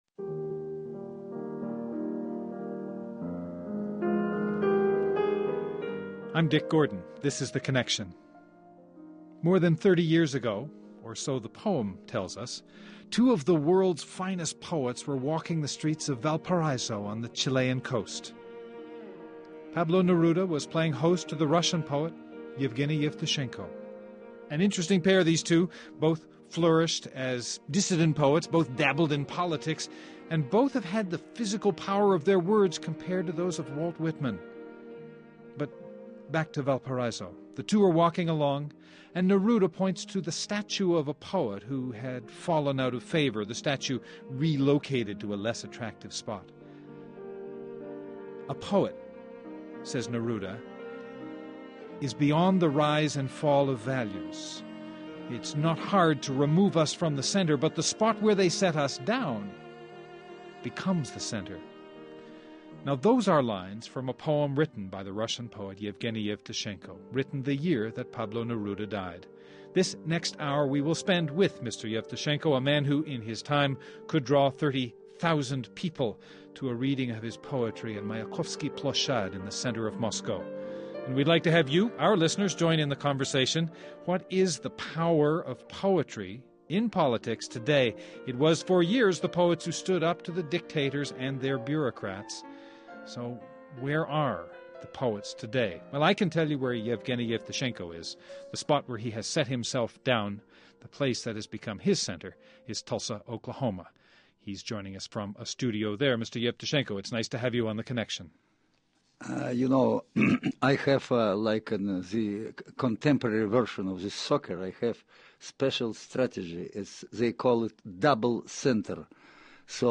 Guests: Yevgeny Yevtushenko, Russian poet